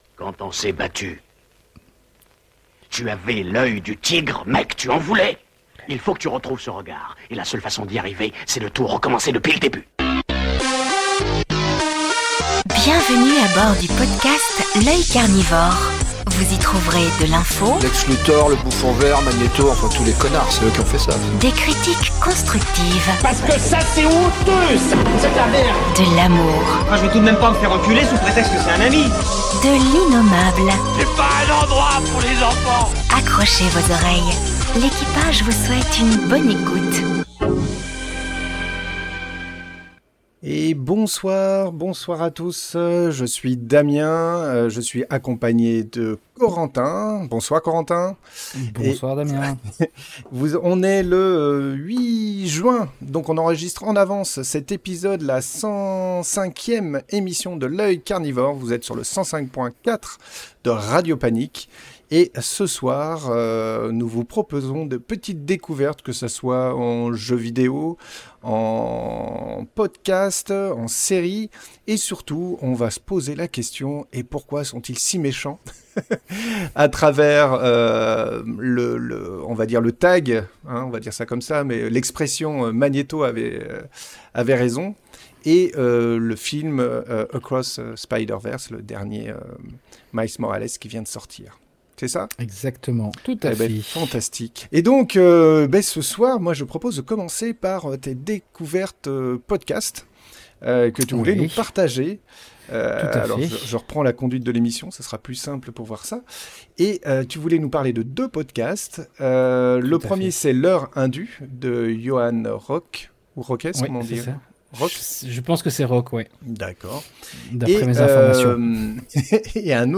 Le studio de L’Œil Carnivore se transforme en antichambre du fantastique pour cette 131ème émission.